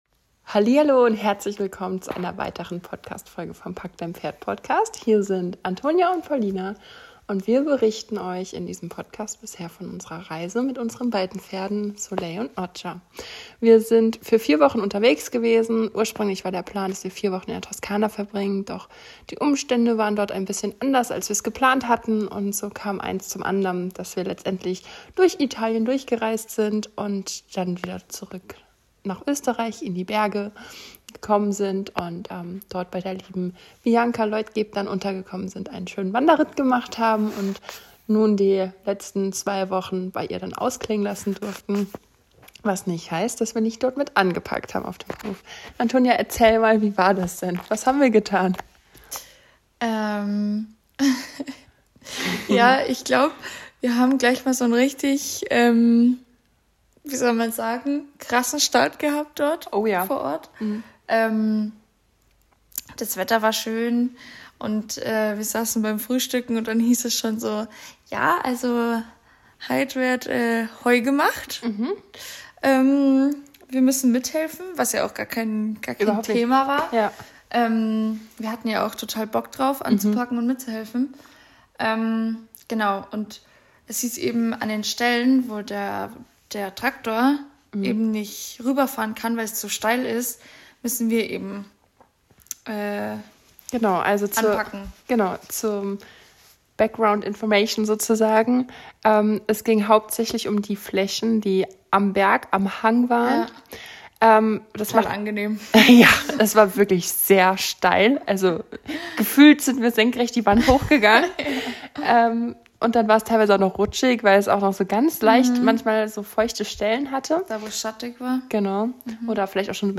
PS: die Aufnahme dieses Podcasts erfolgte sehr spontan im Bett. Daher verzeihe bitte die Tonqualität, wir wollten Dir unsere Erlebnisse dennoch nicht vorenthalten.